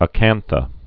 (ə-kănthə)